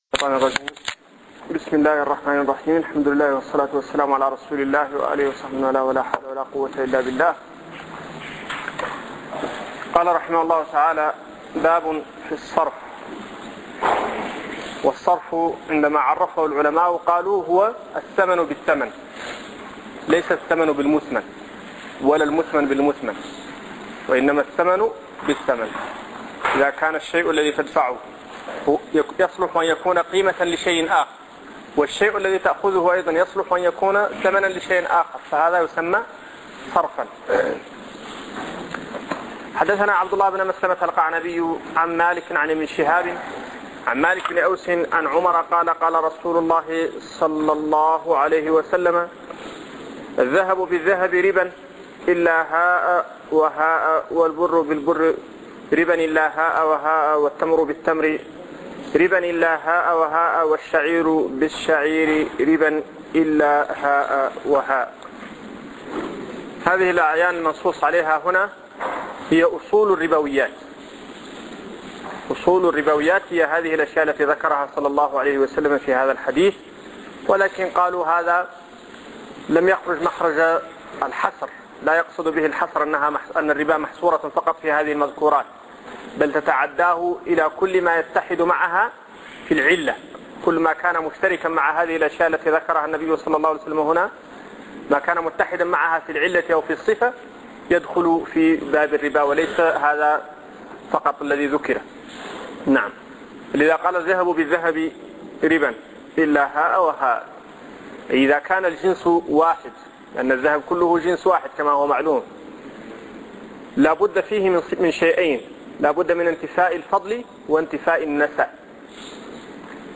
شرح كتاب البيوع من سنن أبي داود الدرس 08